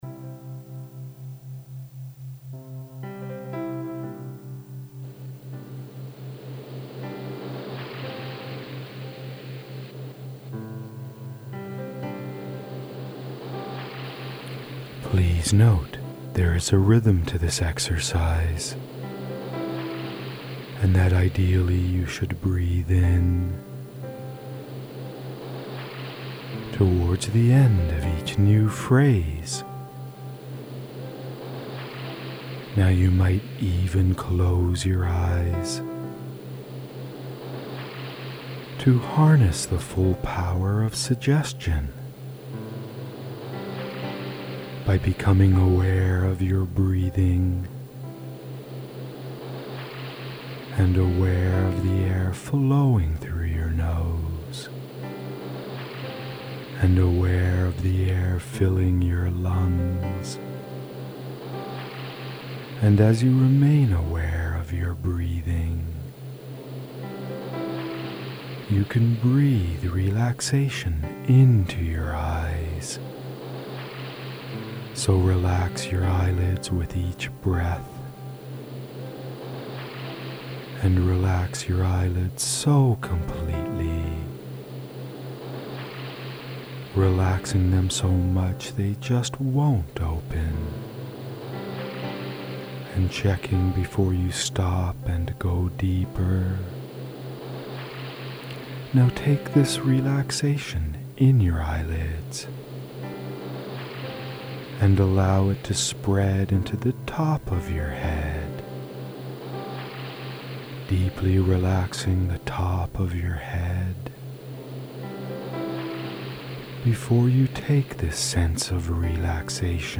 For best results listen to these with stereo headphones.
The Toronto Hypnotherapist's "Relax and Restore" This recording is designed to help you to deeply relax and restore your body to its natural state of health and has been designed to help you enter the hypnotic state that exists between waking and sleep where your subconscious is most receptive to suggestion. Binaural tones have been added to this recording to enhance this process.